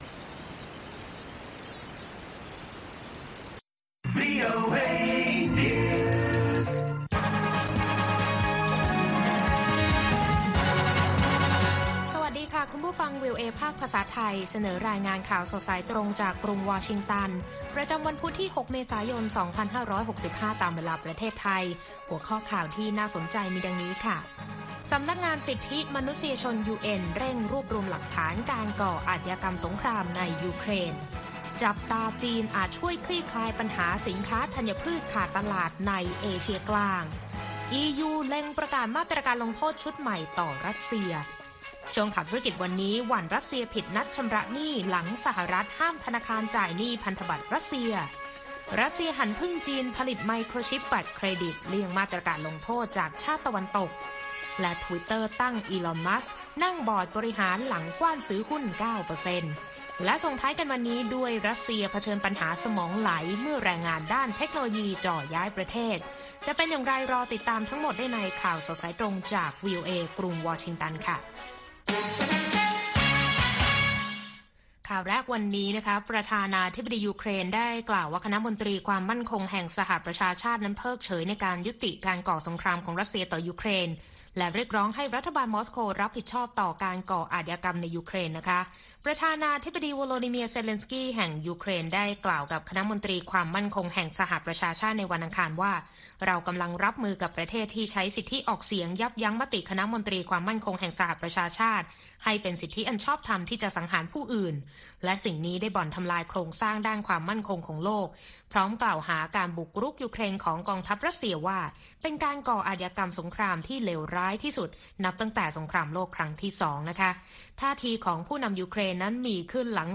ข่าวสดสายตรงจากวีโอเอ ภาคภาษาไทย วันพุธ ที่ 6 เมษายน 2565